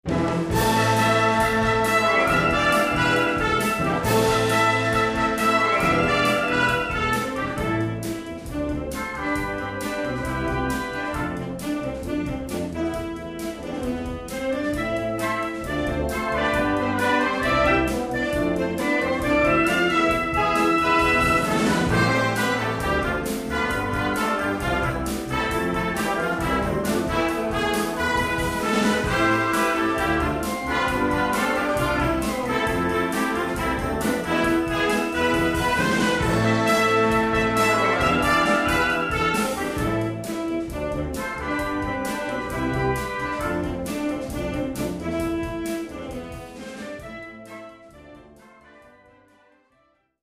Chant et Piano (Baryton ou Mezzo-Soprano)